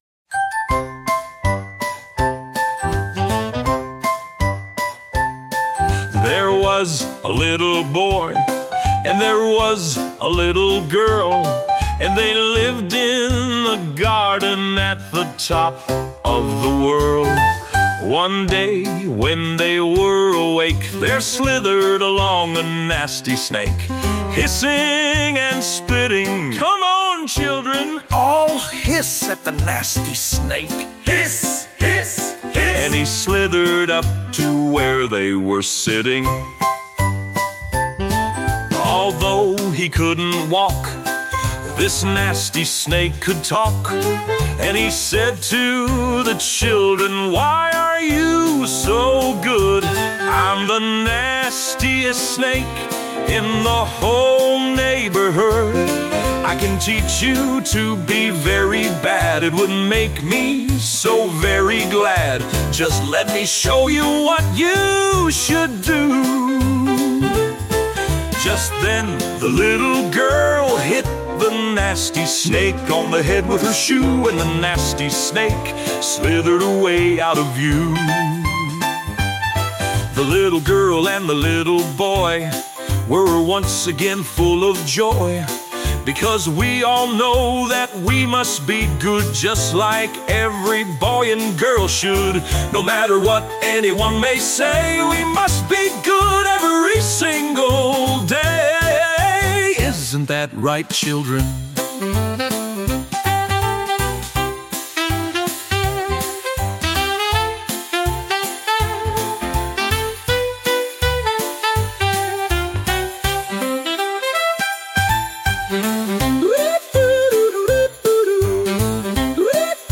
playful and moral-driven children’s song